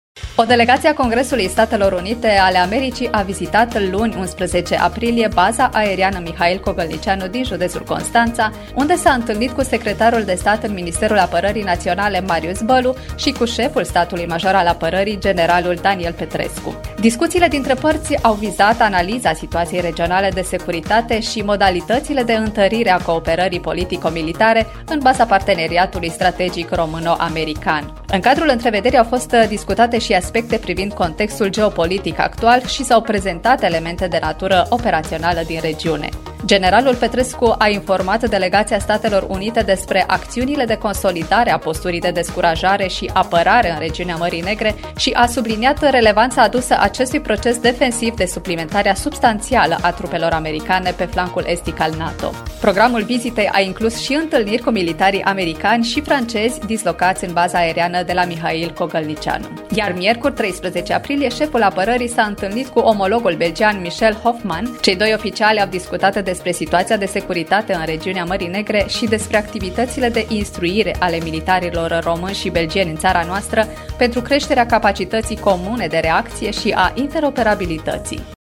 realizator rubrică